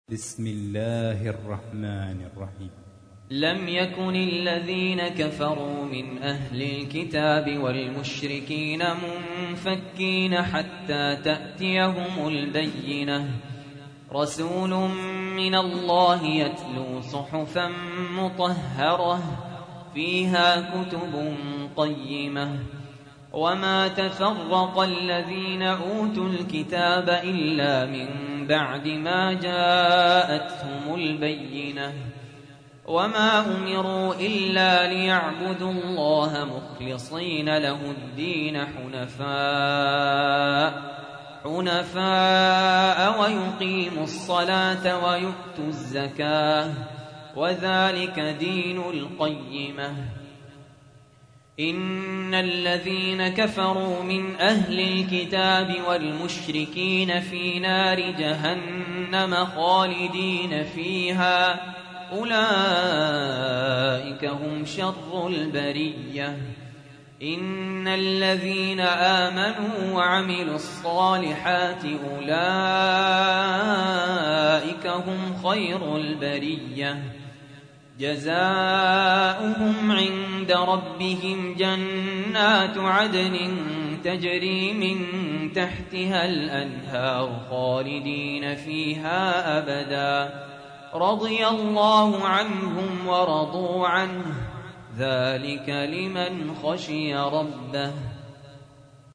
تحميل : 98. سورة البينة / القارئ سهل ياسين / القرآن الكريم / موقع يا حسين